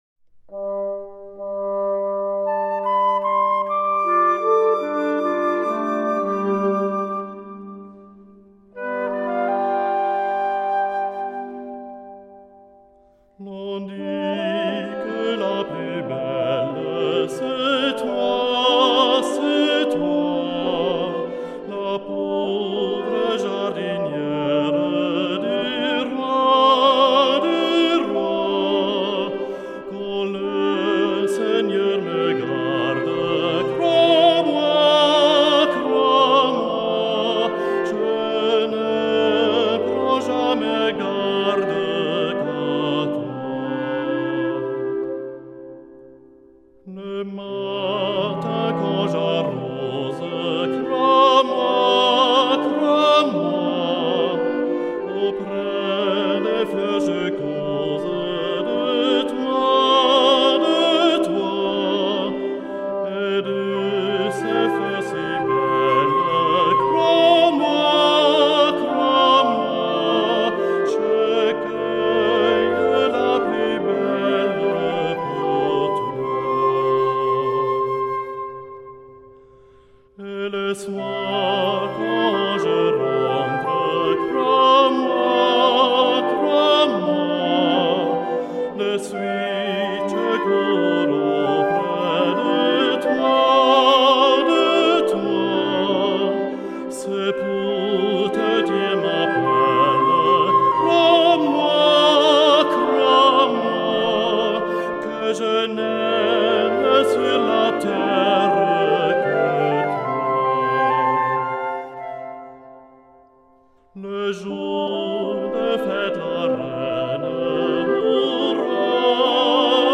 Zurich Boys’ Choir – The most beautiful Swiss folk songs and tunes (Vol. 2)
Trad, arr., André Scheurer. Instrumental accompaniment